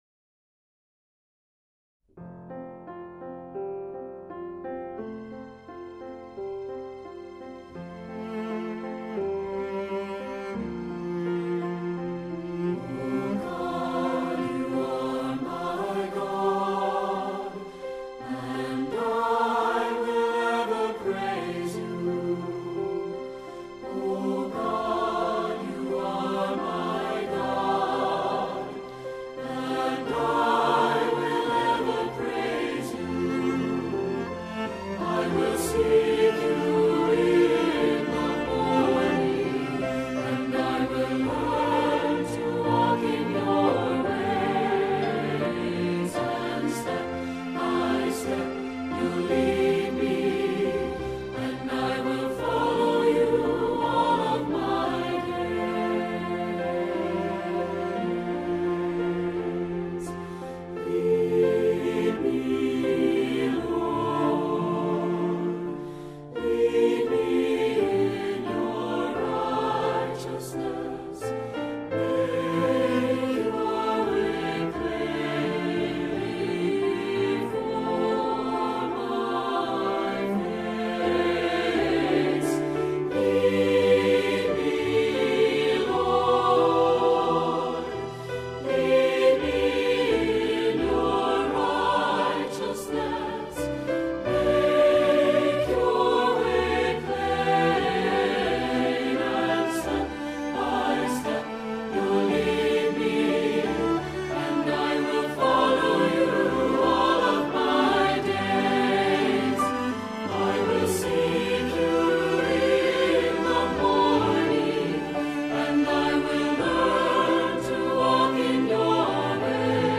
Our service begins with the singing one of two hymns, the more contemporary song being found here and the traditional hymn being here.
Welcome to this time of worship.